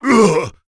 Bernheim-Vox_Damage_02.wav